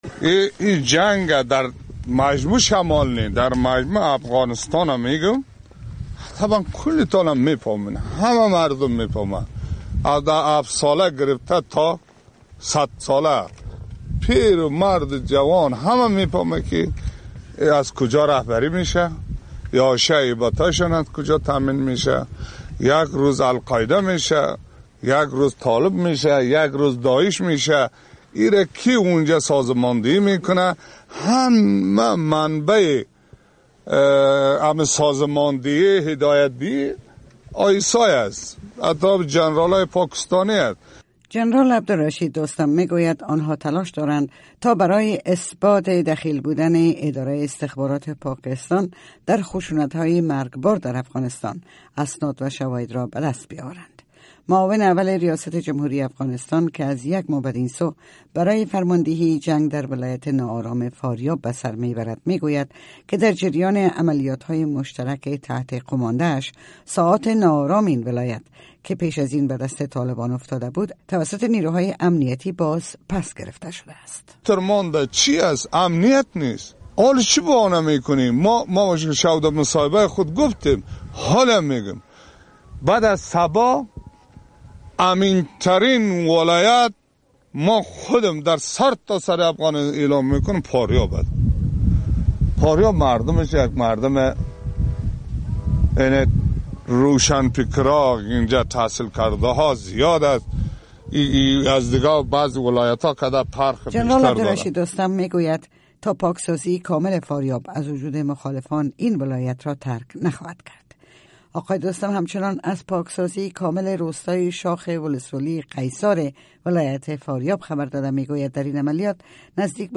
The URL has been copied to your clipboard No media source currently available 0:00 0:03:08 0:00 لینک دانلود 64 kbps | ام‌پی ۳ برای شنیدن مصاحبه در صفحۀ جداگانه اینجا کلیک کنید